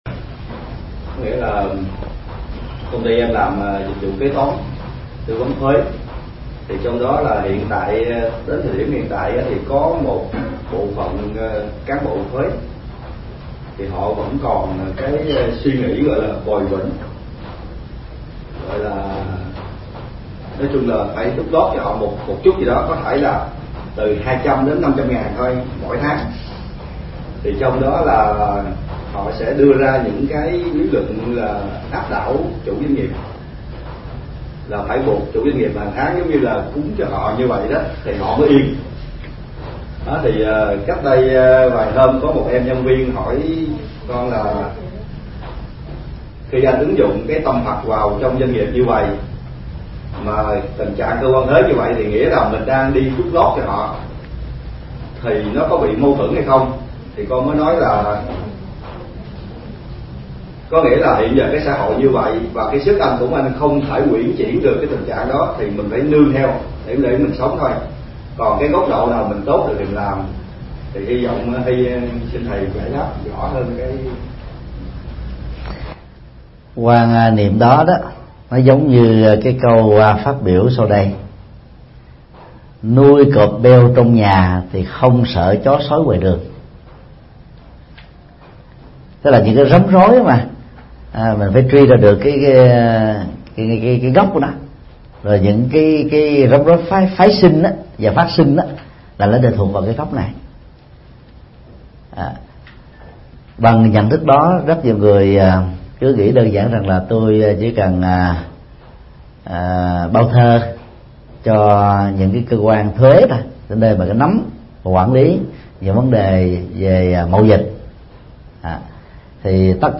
Vấn đáp: Ứng xử tình huống khi bị yêu cầu hối lộ